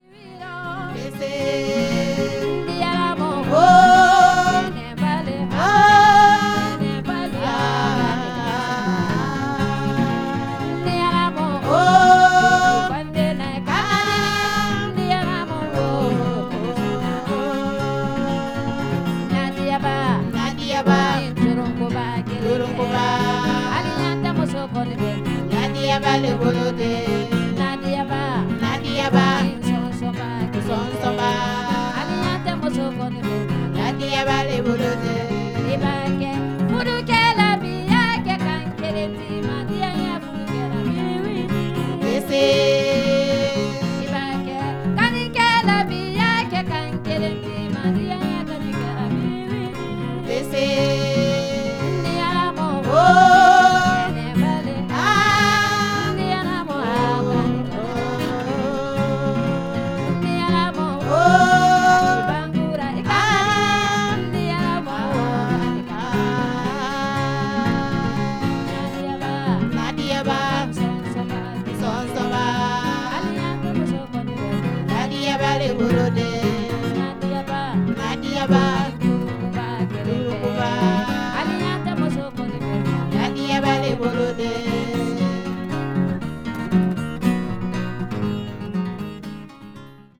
フランス・パリでの録音作品。
穏やかな気持ちになります。2トラックのビデオ・テープにダイレクト・レコーディングされた音源とのことで、素晴らしい音質。